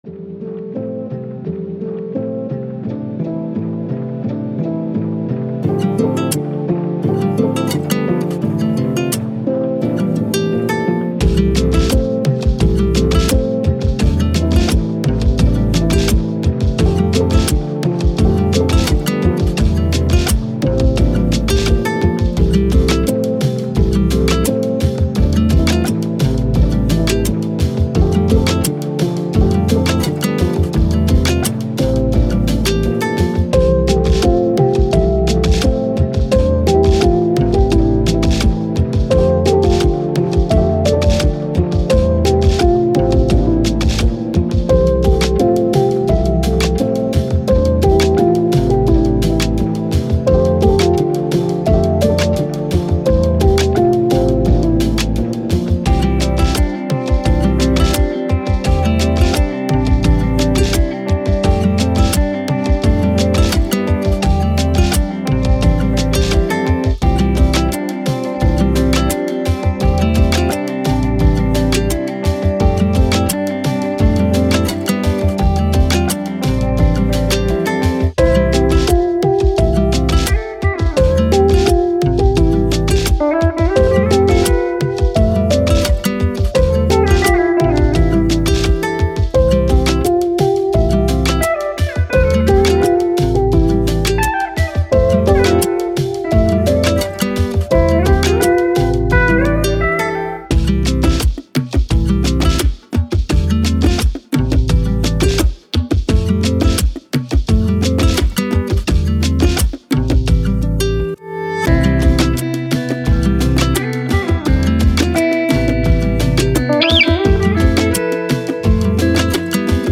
Downtempo, Chilled, Lofi, Journey, Story